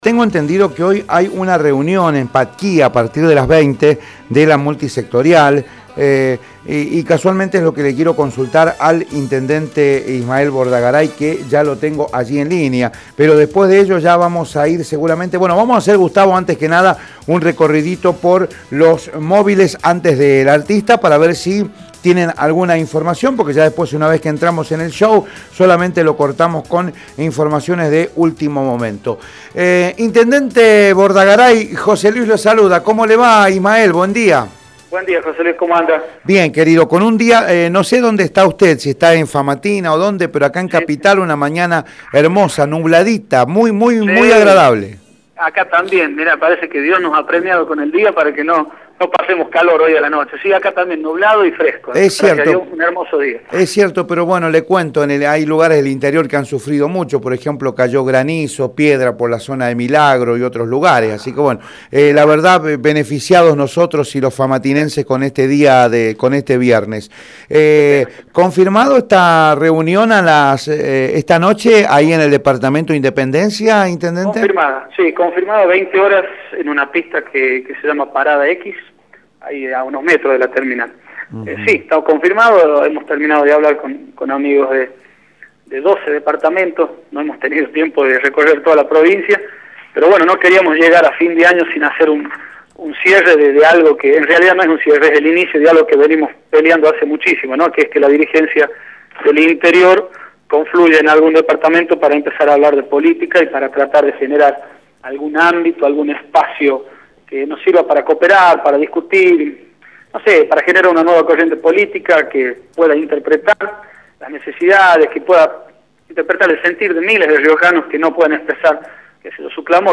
Julio Martínez, diputado nacional, por Radio La Red